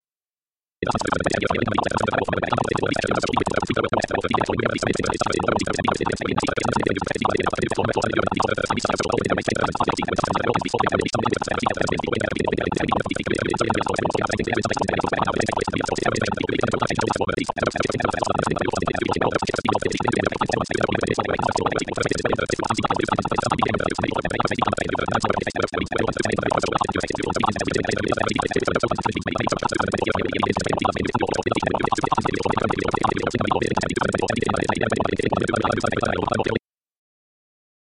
(Please use headphones or good speakers, phone speakers sound awful and you can’t hear the soundtrack properly)